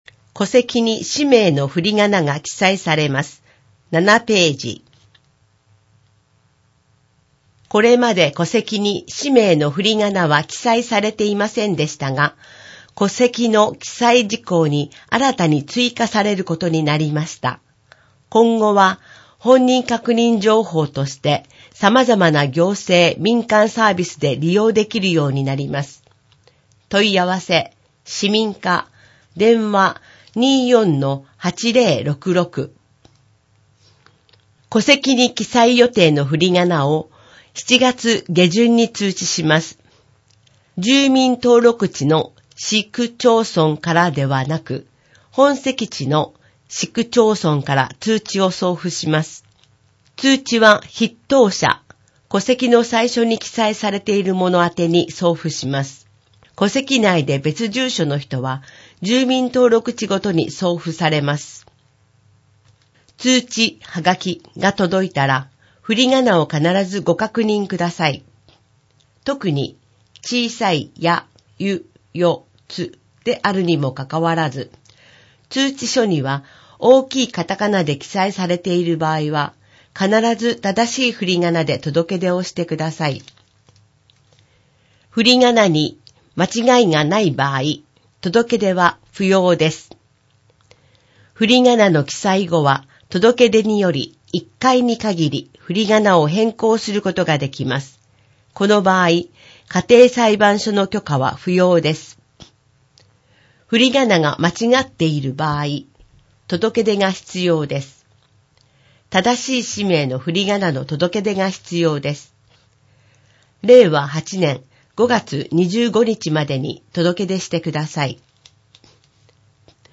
広報こまつの音訳は音訳ボランティアグループ「陽だまり会」の皆さんの協力で行っています。